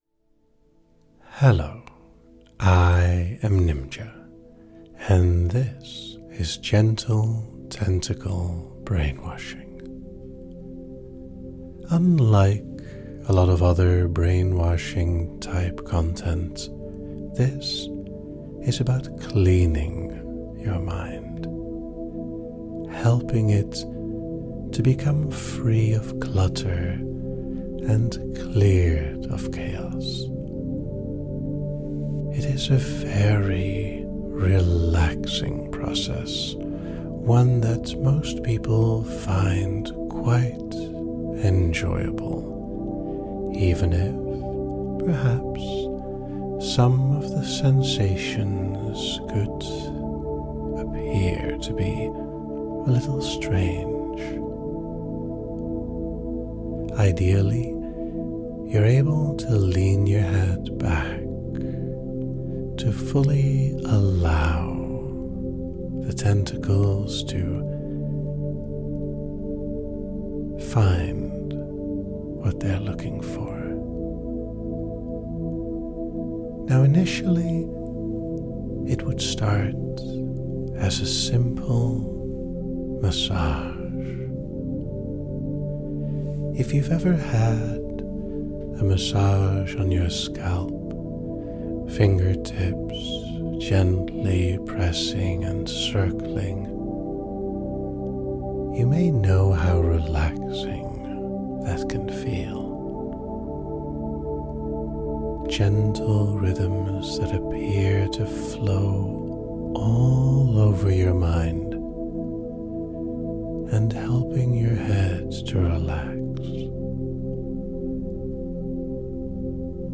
The session uses a countdown to deepen the state of relaxation, emphasizing the n